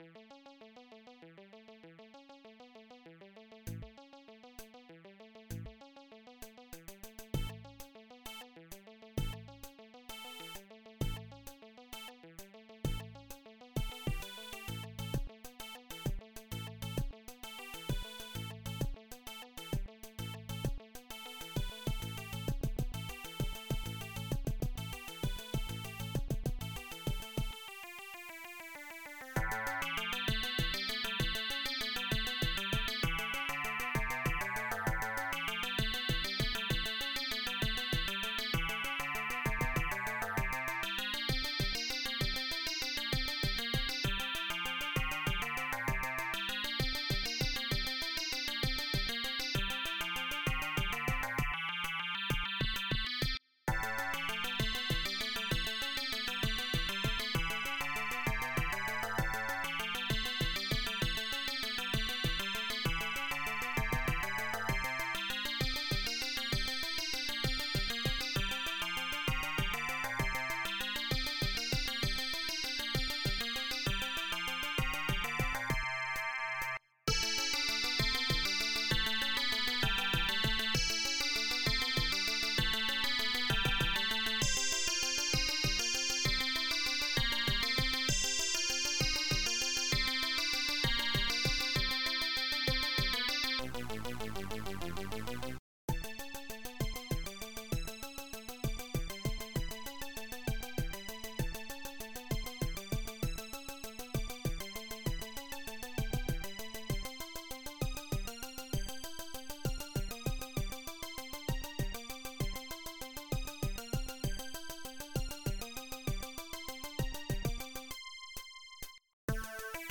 AdLib/Roland Song